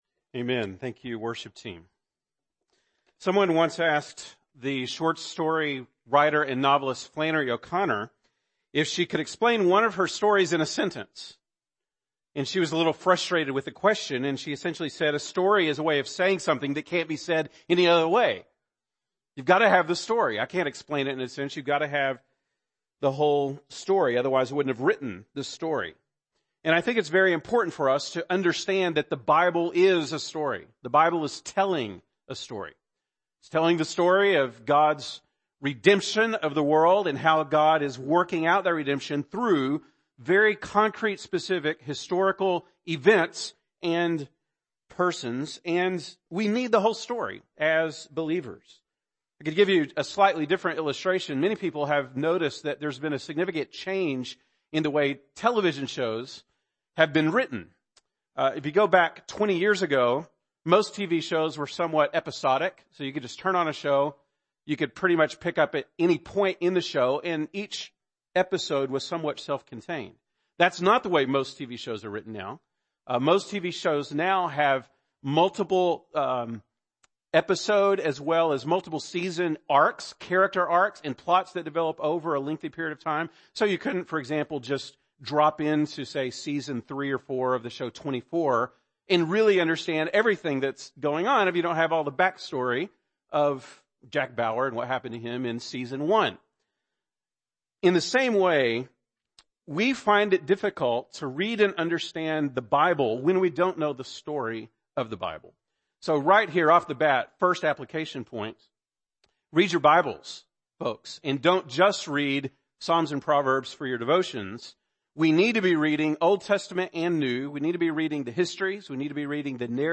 October 15, 2017 (Sunday Morning)